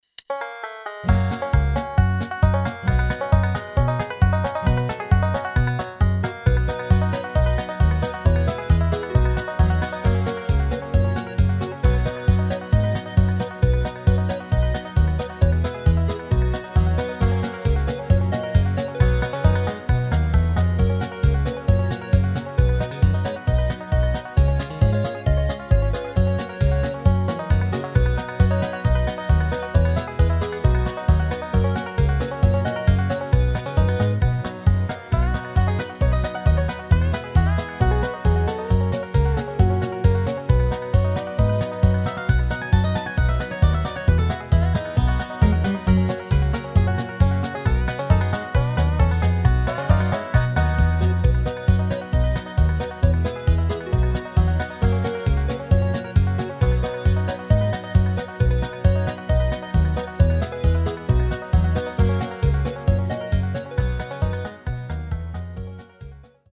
Rubrika: Folk, Country
Předehra: banjo + E1, E2, A1, A2, A3, A4